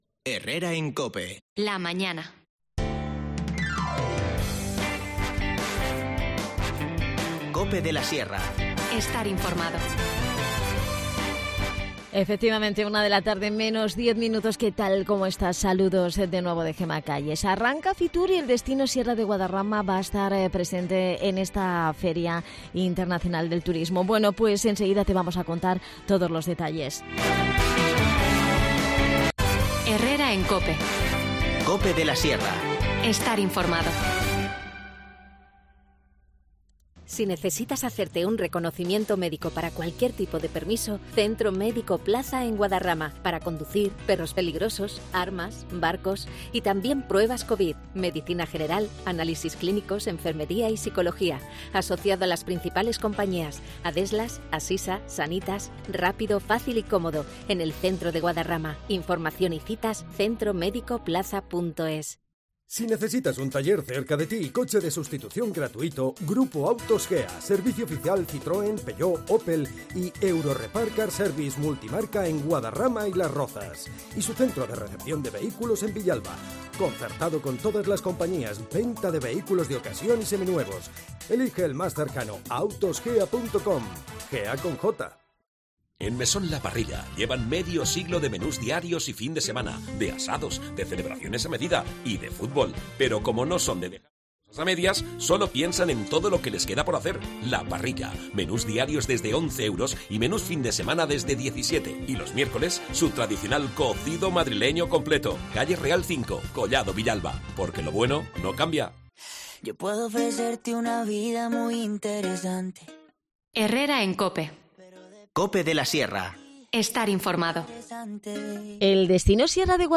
Charlamos con Jesús Redondo, miembro de la Junta Directiva de ADESGAM y edil de Turismo en El Boalo-Cerceda-Mataelpino.